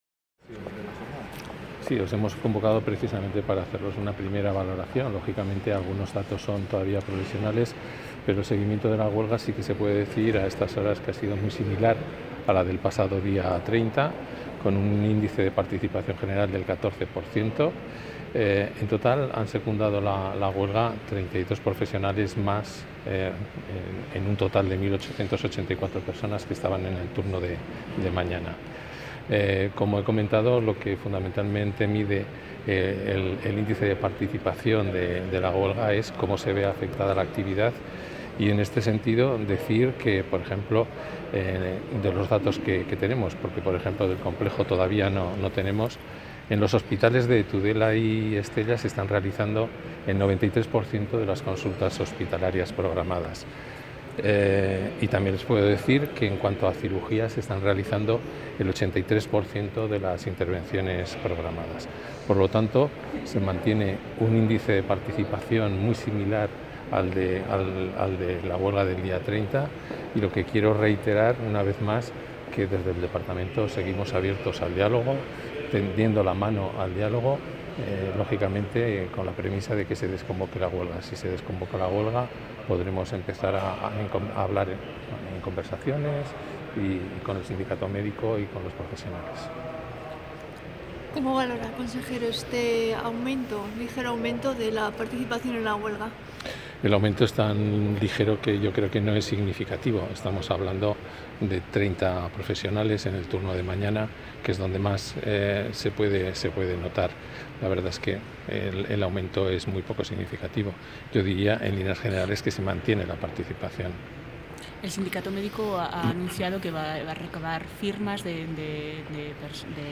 Declaraciones consejero Dominguez